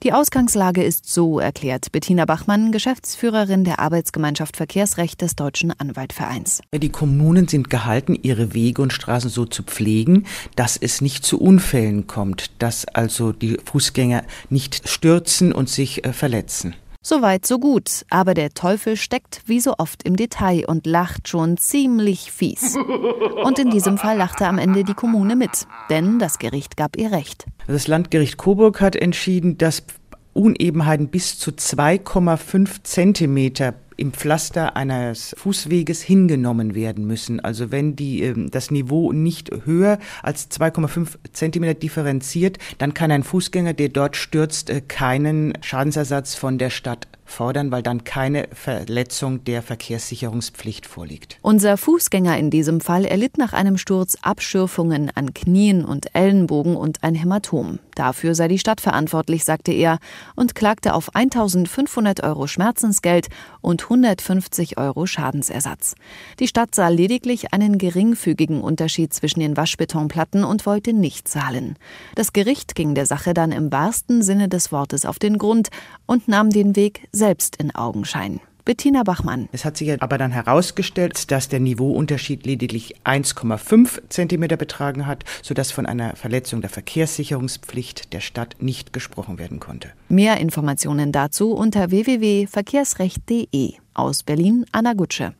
O-Töne / Radiobeiträge, Ratgeber, Recht, , , , , , ,